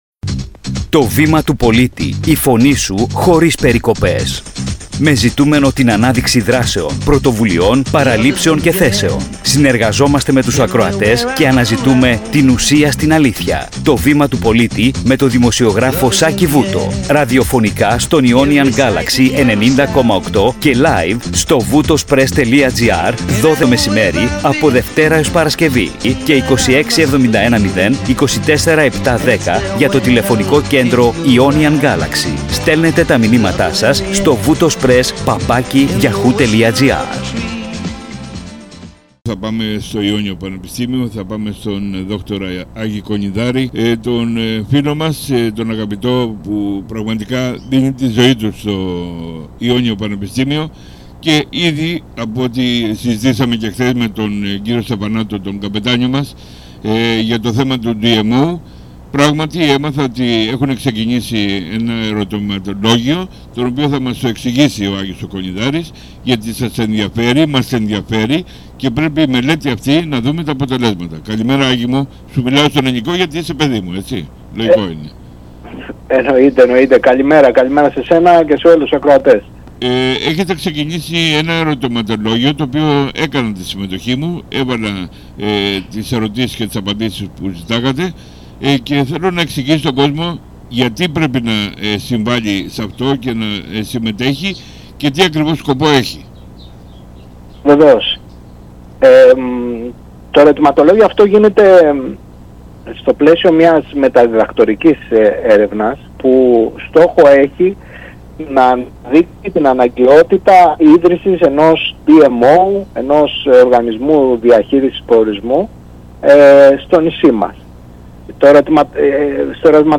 Στην εκπομπή «Το βήμα του πολίτη»